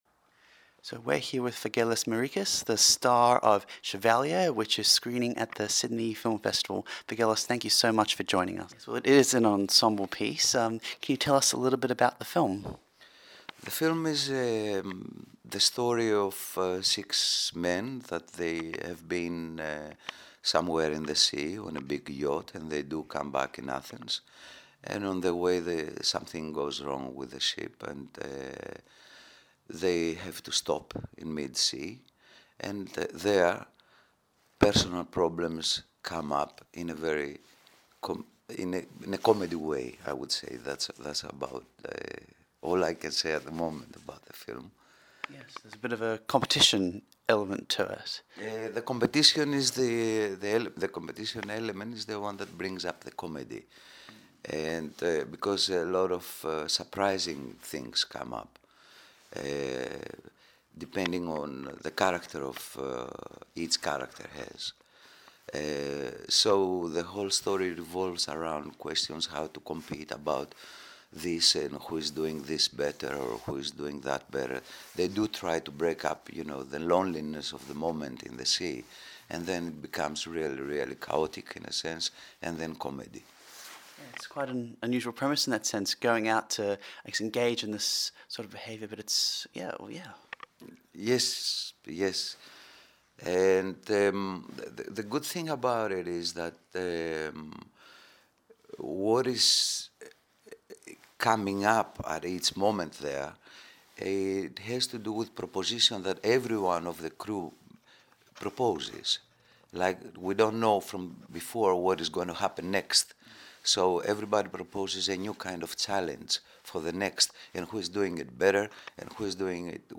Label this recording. Festivals, Interviews